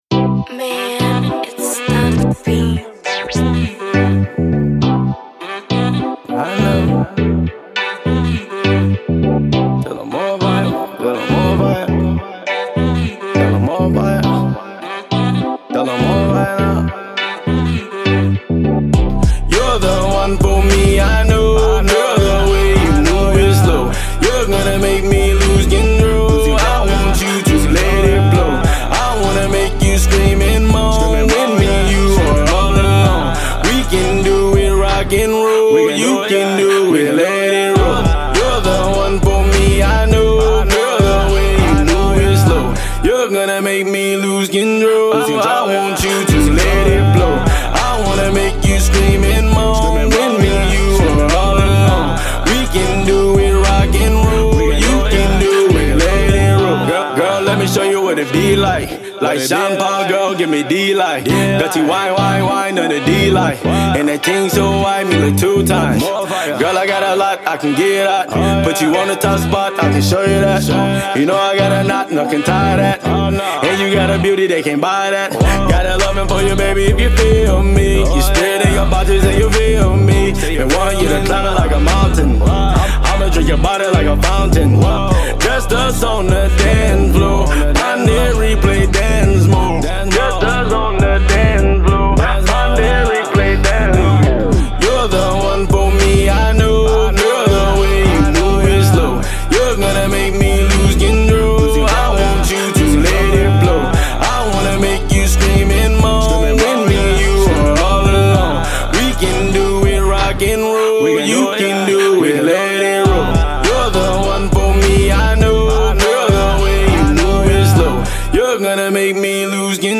Pop
hot hip-hop/island-infused hit